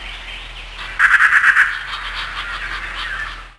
BIRD2.WAV